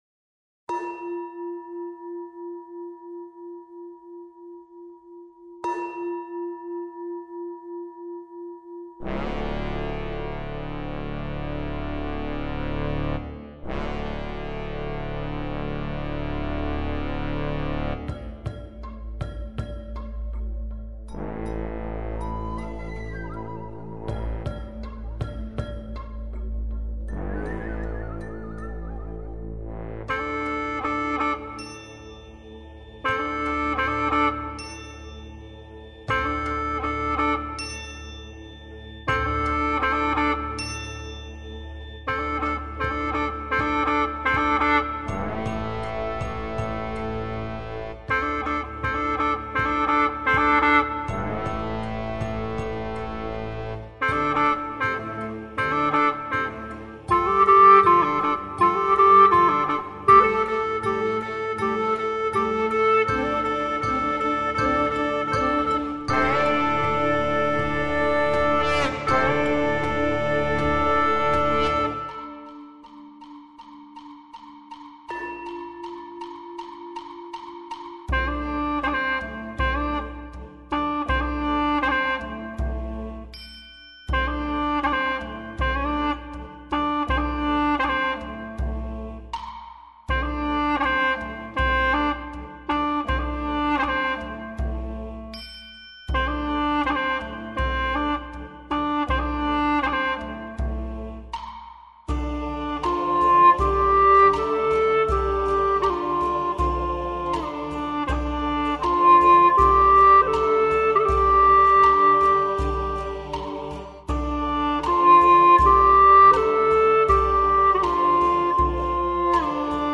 调式 : F 曲类 : 独奏